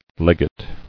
[leg·ate]